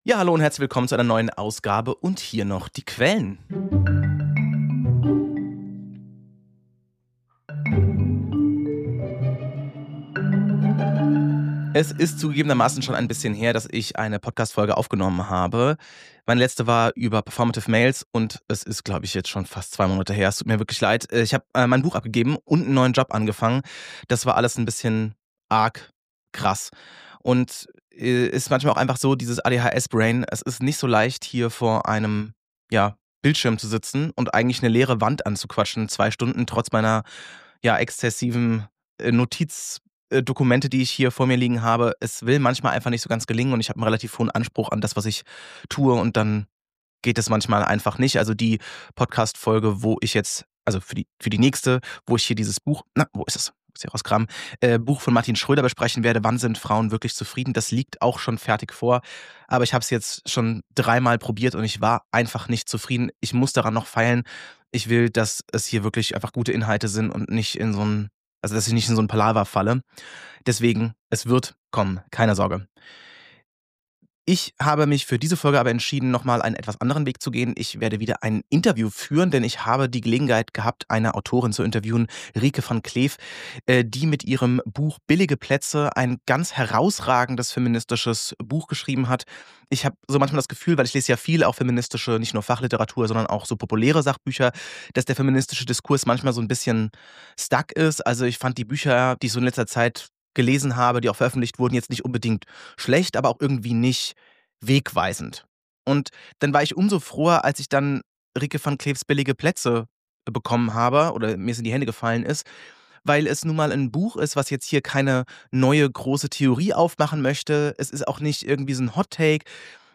Billige Plätze: Wie sexistisch die Musikbranche noch immer ist – Interview